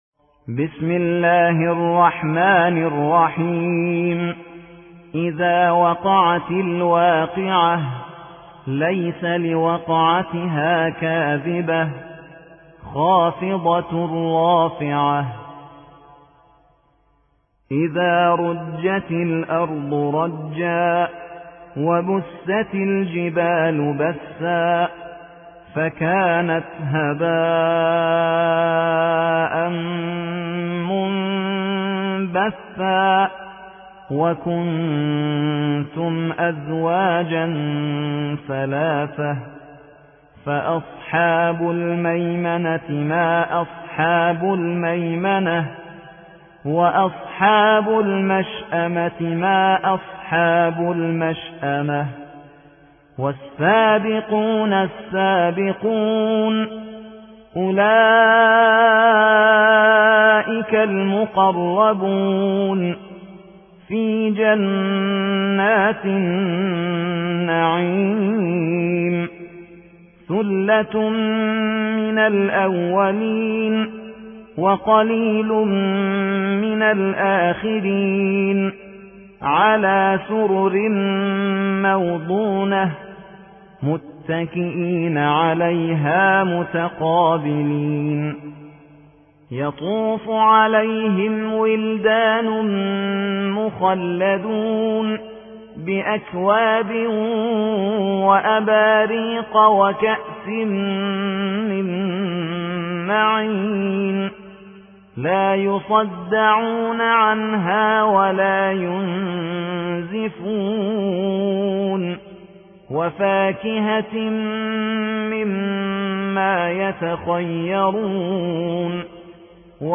56. سورة الواقعة / القارئ